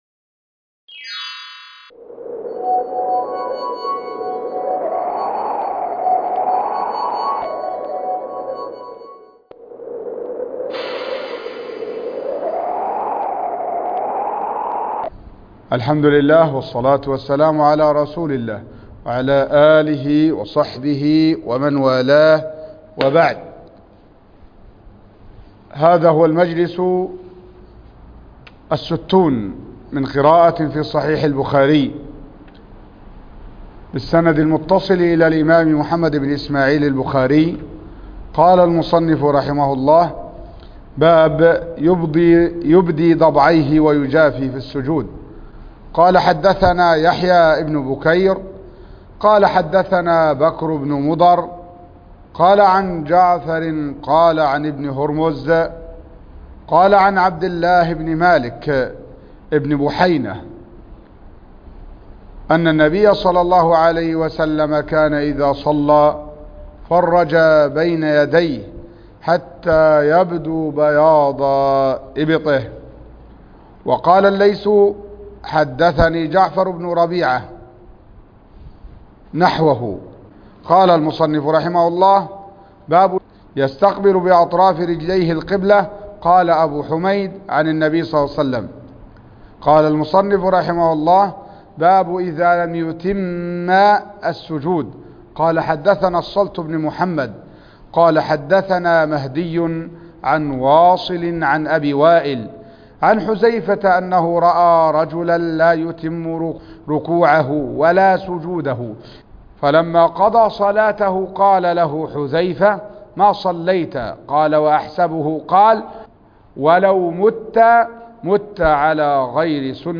الدرس ( 60) قراءة صحيح البخاري